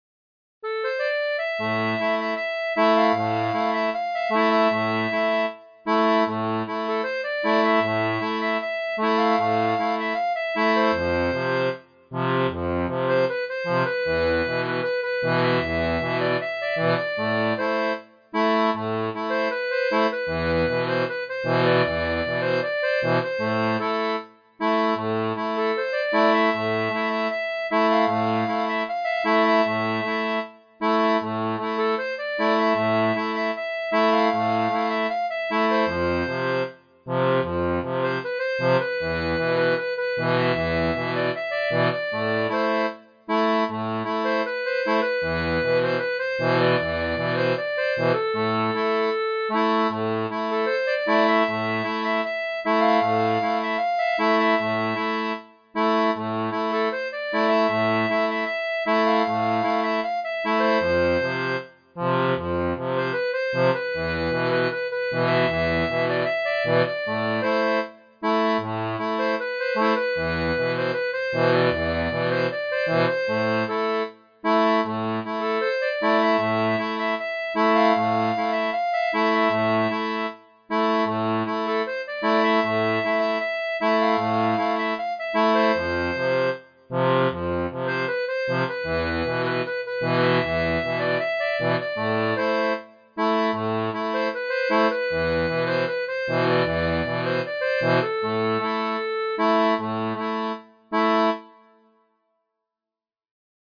Type d'accordéon
Chanson française